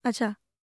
TALK 10.wav